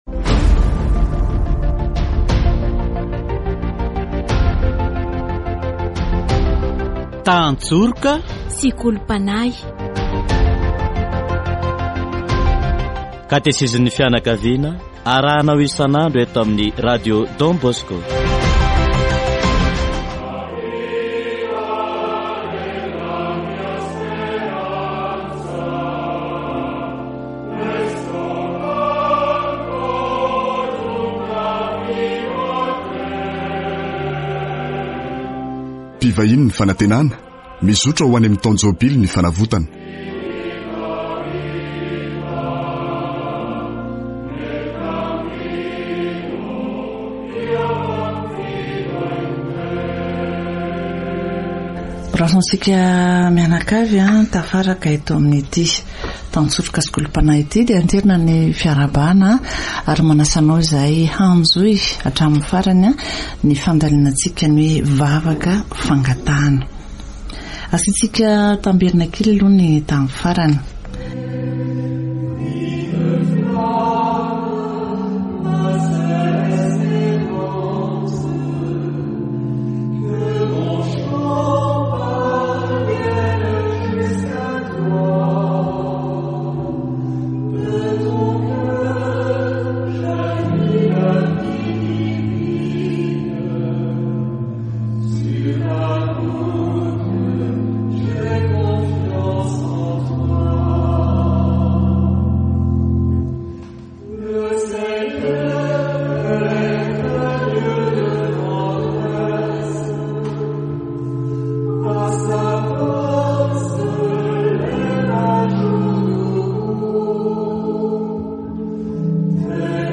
Catéchèse sur La prière de de demande